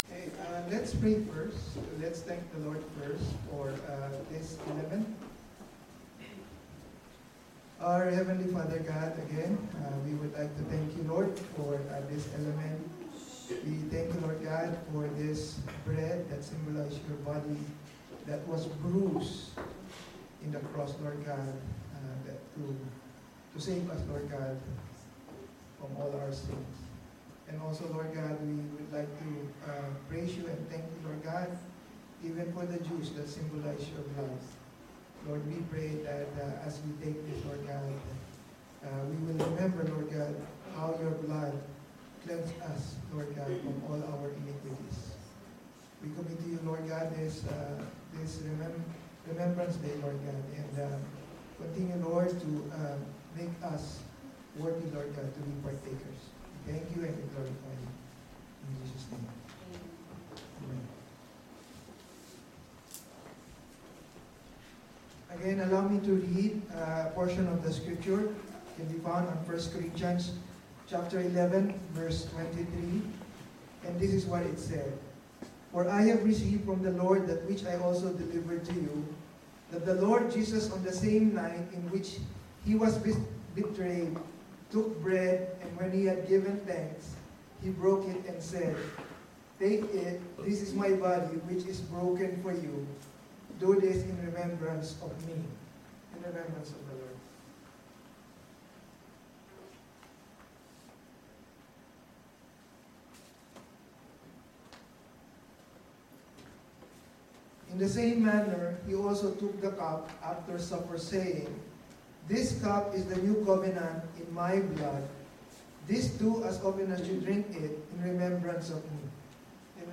Sermon MP3s – Milton Christian Fellowship